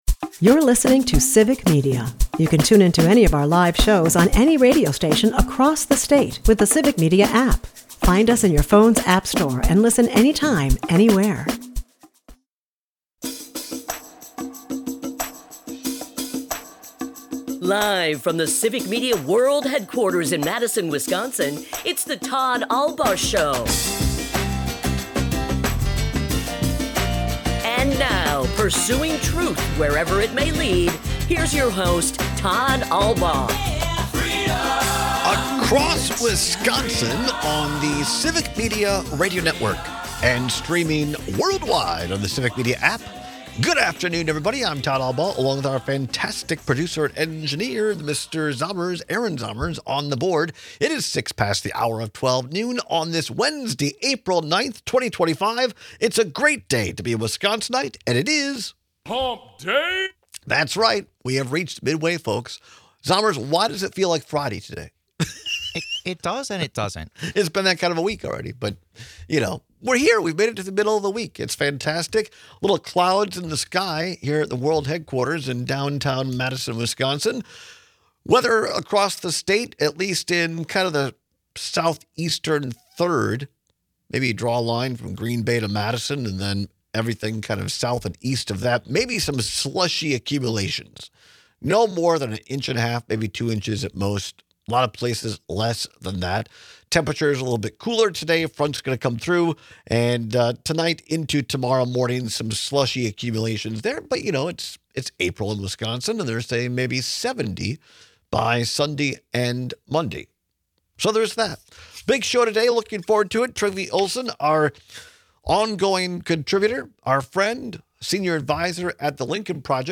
This Wednesday, one of our callers kicks off the discussion on the Trump administration’s self-inflicted wounds.